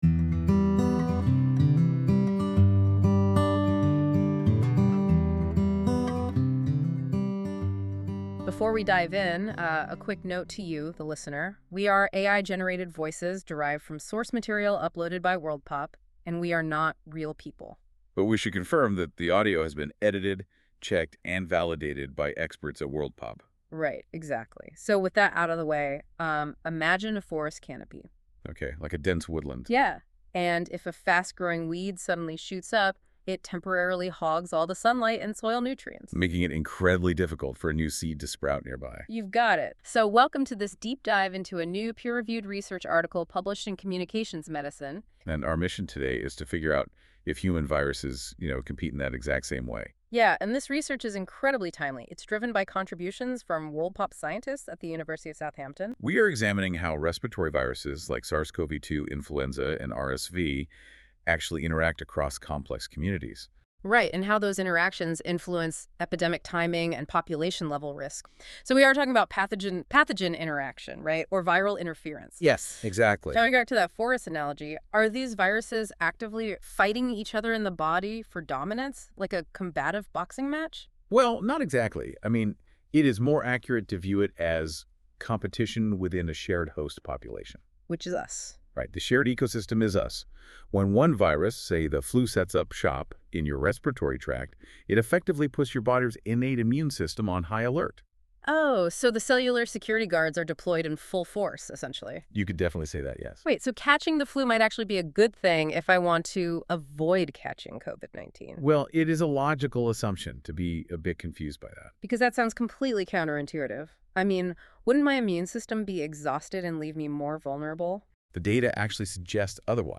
This feature uses AI to create a podcast-like audio conversation between two AI-derived hosts that summarise key points of the document - in this case the full journal article linked below.
Music: My Guitar, Lowtone Music, Free Music Archive (CC BY-NC-ND)